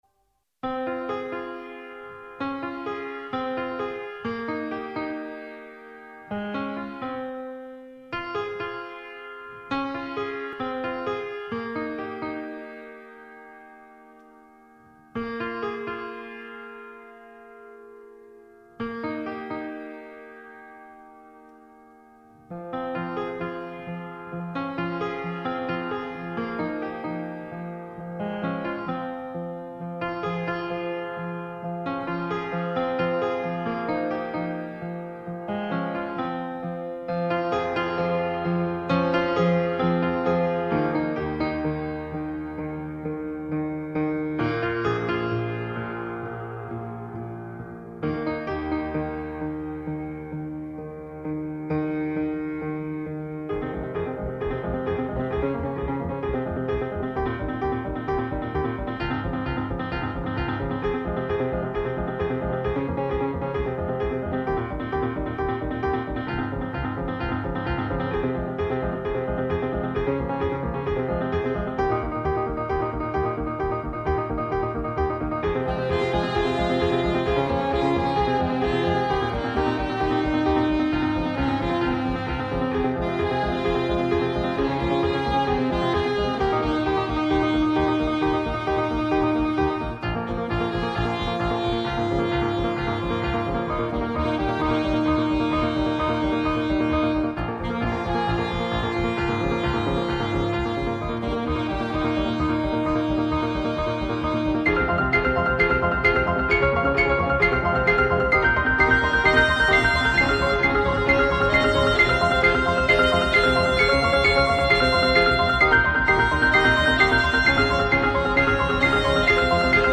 Συνεντεύξεις